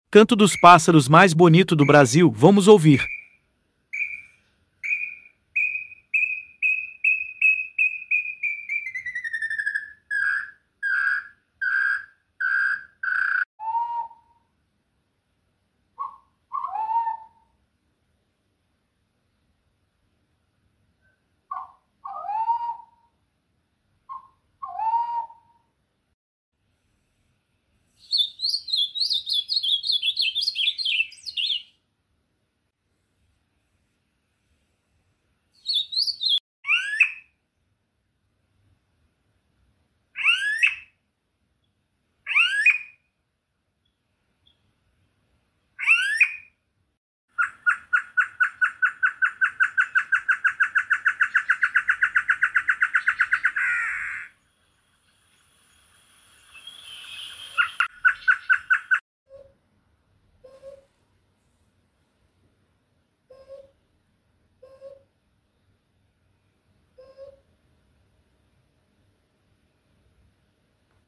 canto dos pássaros mais bonito sound effects free download